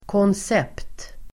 Uttal: [kåns'ep:t]